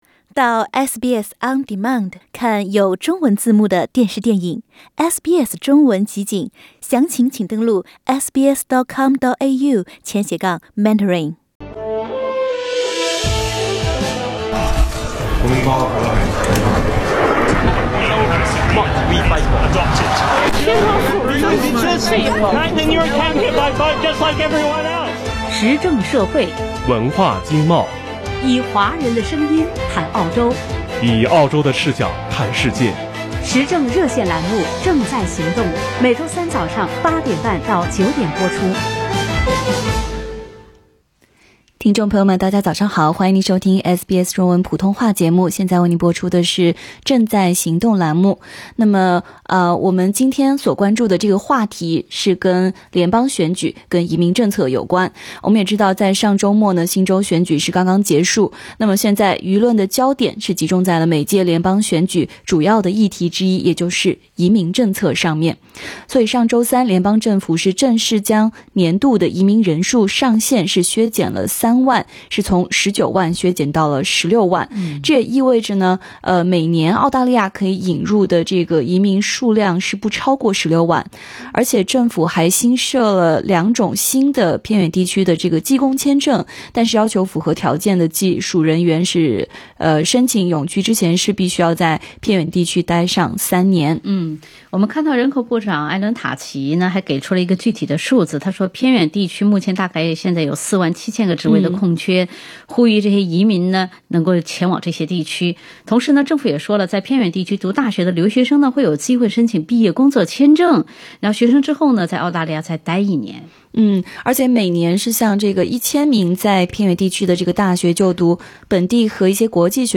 在节目中，听众们表达了自己的观点。